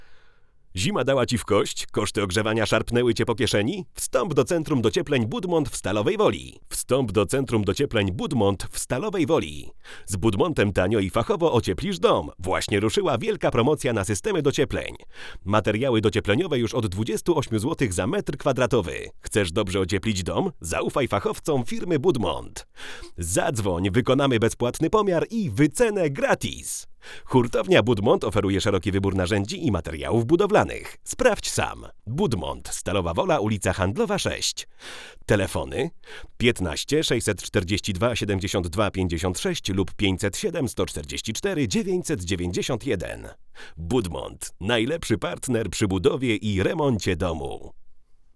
Szumy jak najbardziej w normie.
Helios brzmi równie dobrze w niskich jak i w wysokich pasmach.
Mięsiście, ciepło i blisko.